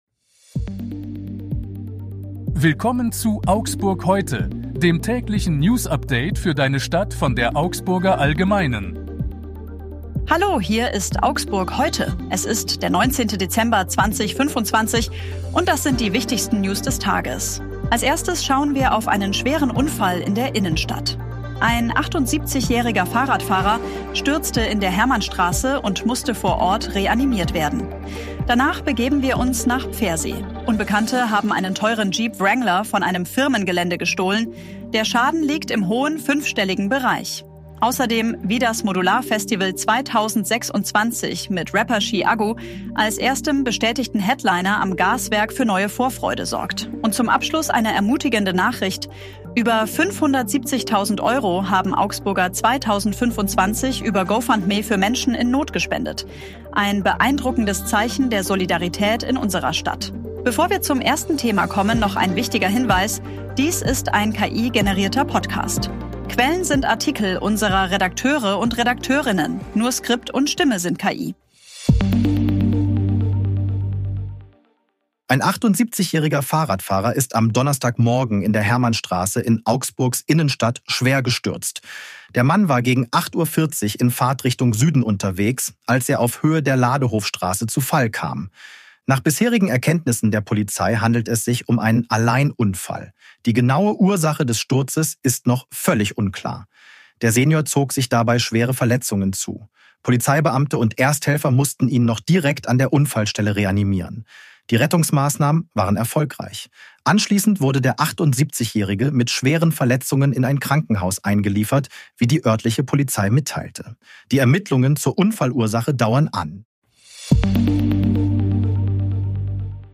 Redakteurinnen. Nur Skript und Stimme sind KI.